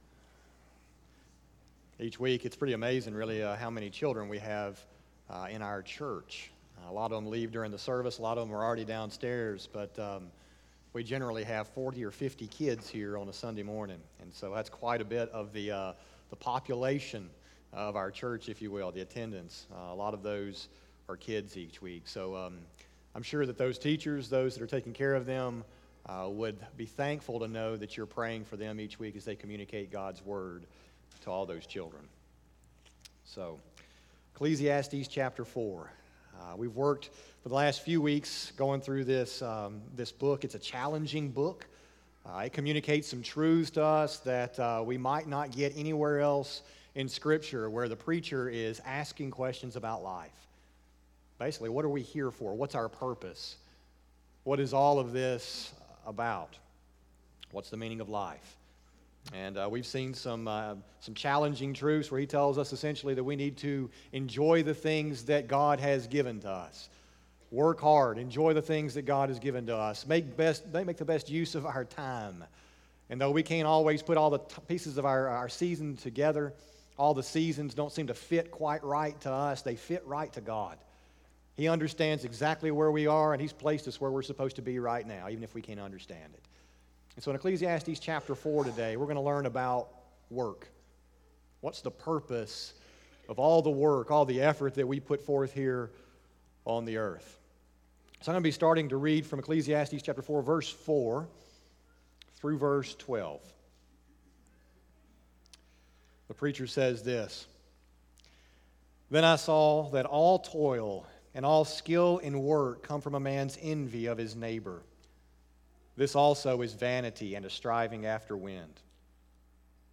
sermon012818a.mp3